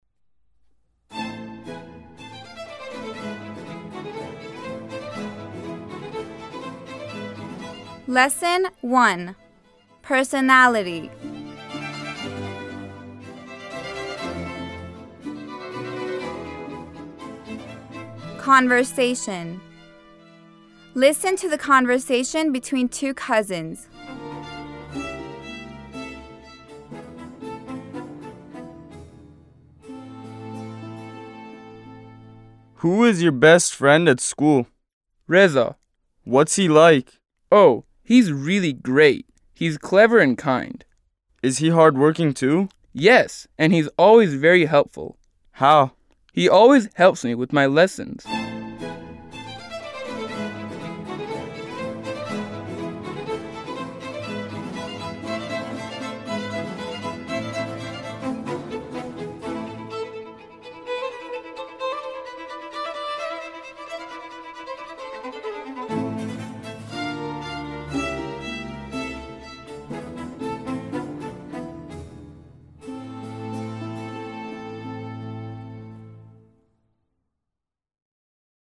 9-L1-Conversation
9-L1-Conversation.mp3